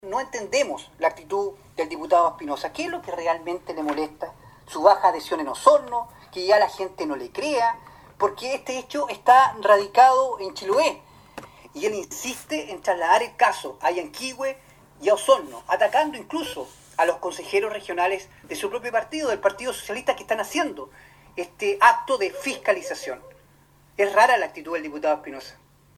25-CONSEJERO-FRANCISCO-REYES-2.mp3